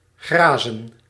Ääntäminen
France (Paris): IPA: [pɛtʁ]